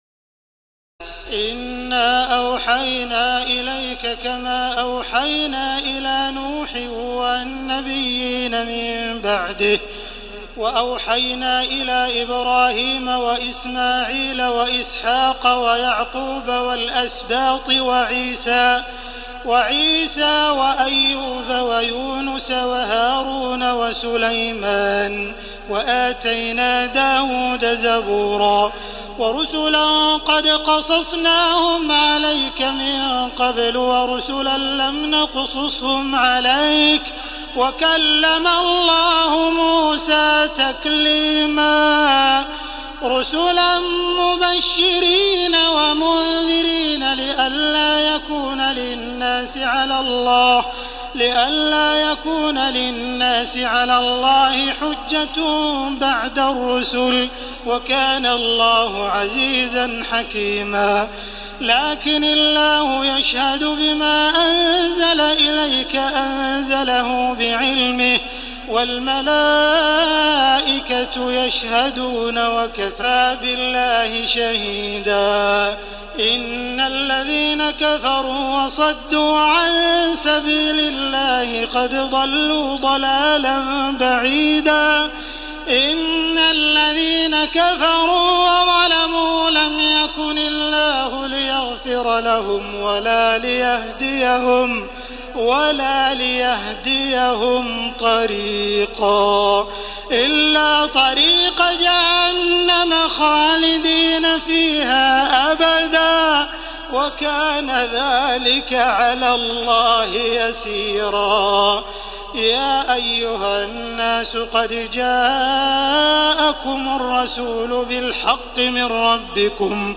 تراويح الليلة الخامسة رمضان 1433هـ من سورتي النساء (163-176) و المائدة (1-40) Taraweeh 5 st night Ramadan 1433H from Surah An-Nisaa and AlMa'idah > تراويح الحرم المكي عام 1433 🕋 > التراويح - تلاوات الحرمين